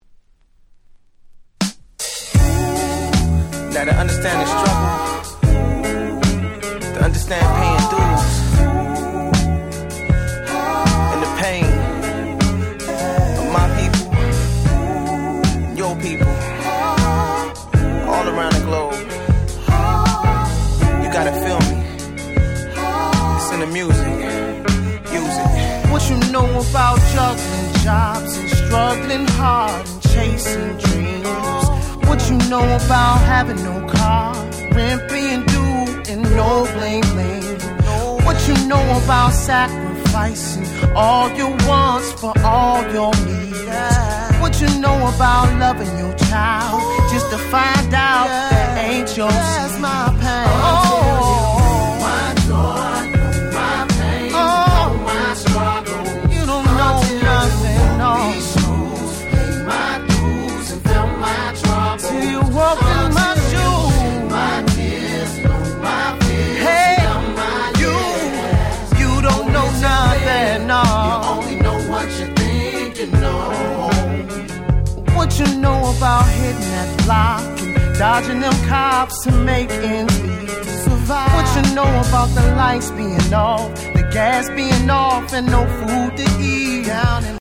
03' Very Nice R&B / Neo Soul !!